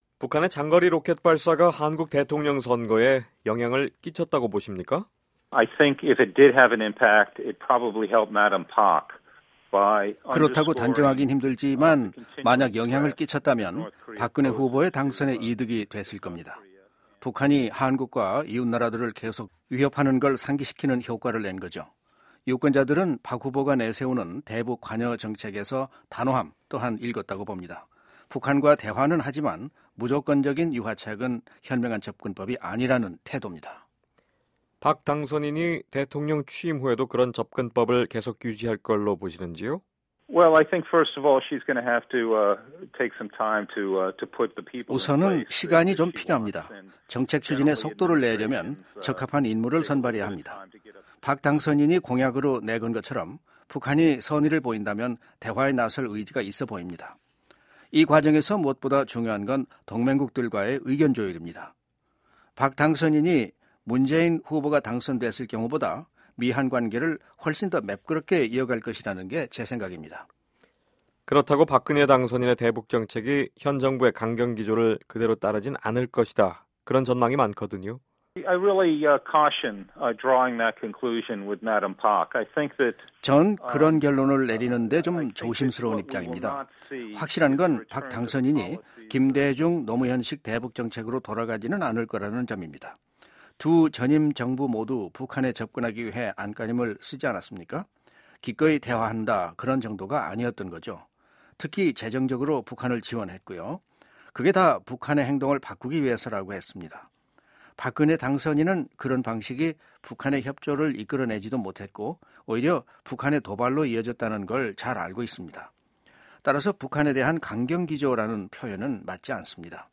박근혜 후보가 한국의 18대 대통령으로 당선되면서 한국의 향후 대북정책과 대미관계가 어떤 방향으로 나갈지 주목되고 있습니다. 저희 VOA는 미 국무부에서 한반도 정책을 다루던 전직 관리들과의 인터뷰를 통해 이 문제를 짚어보는 시간 마련했습니다. 오늘은 두번째 순서로 데이비드 스트로브 전 국무부 한국과장의 분석과 전망 들어보겠습니다.